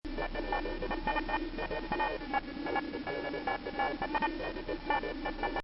man_computalk2.mp3